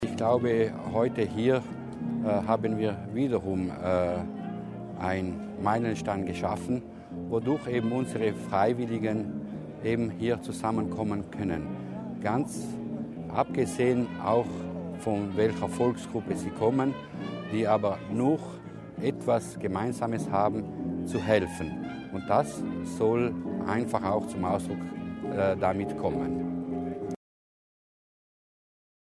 Landesrat Mussner über die Wichtigkeit der Unterstützung der Freiwilligenarbeit